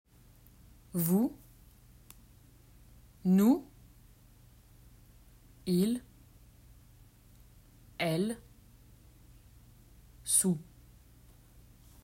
Listen and repeat these common words in which the final -s is silent.